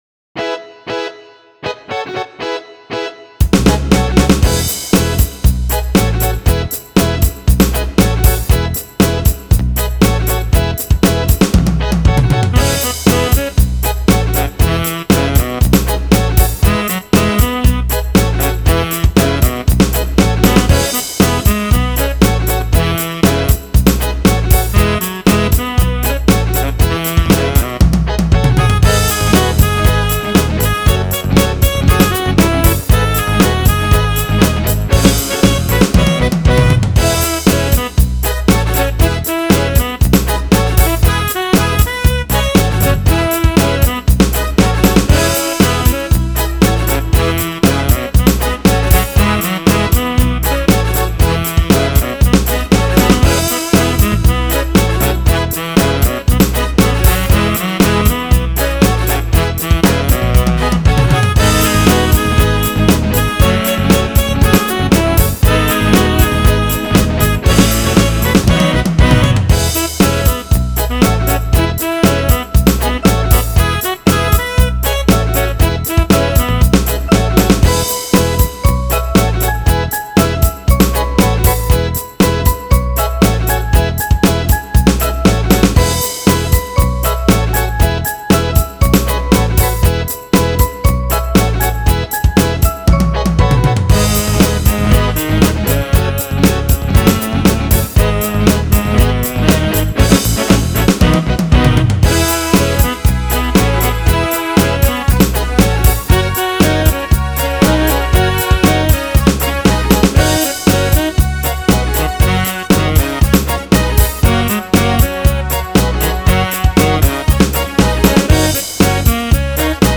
th7cuvv009  Download Instrumental